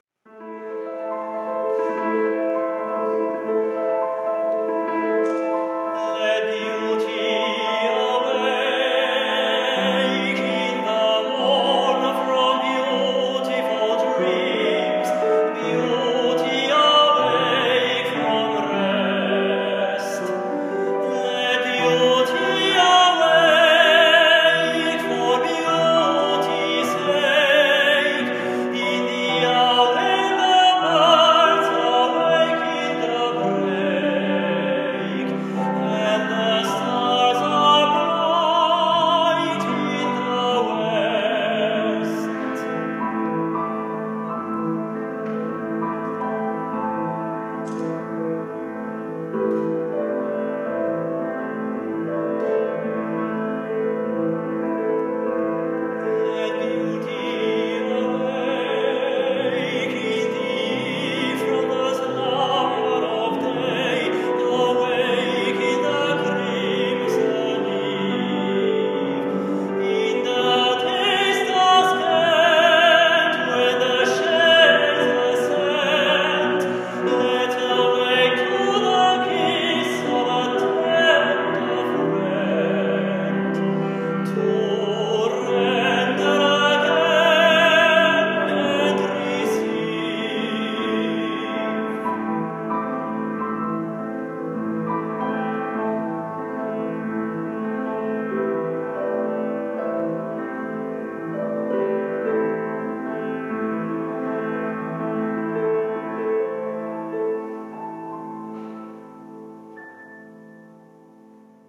lyrische tenor
Live! Recording on Dutch National Radio 4 2016 F.P.Tosti: Non t'amo piu